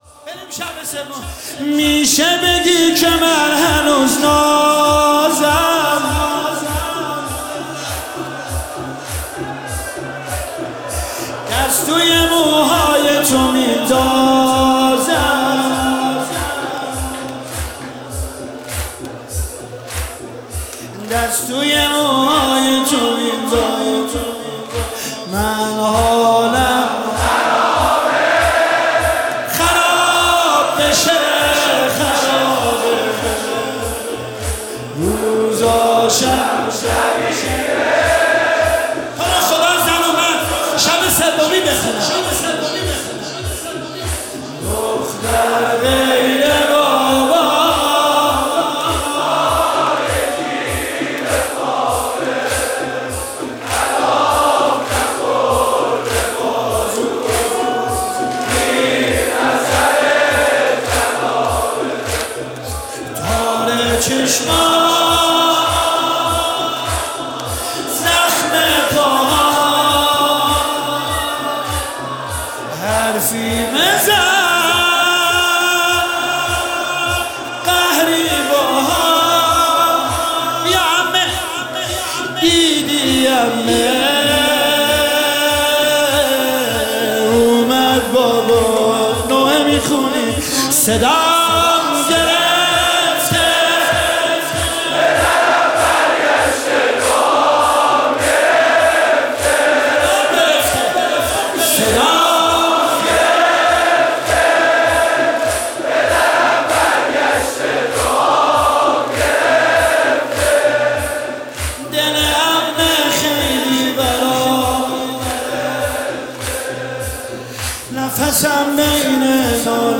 مداحی زمینه روضه ای